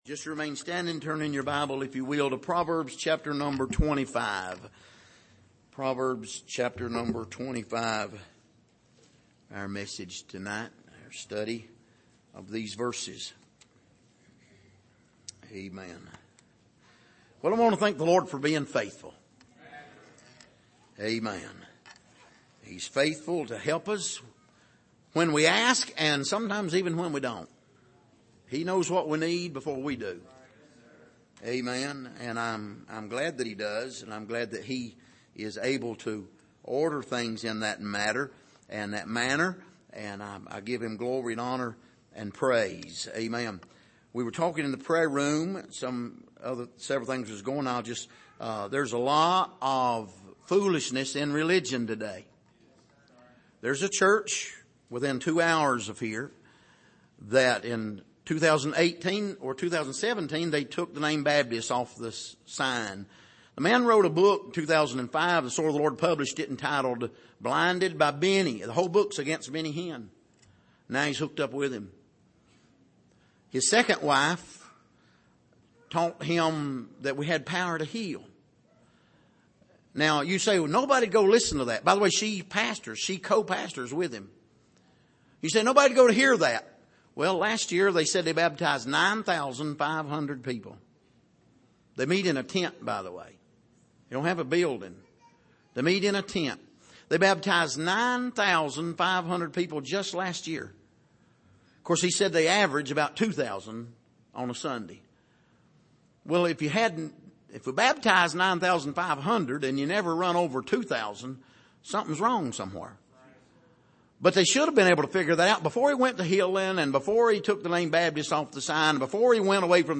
Passage: Proverbs 25:1-7 Service: Sunday Evening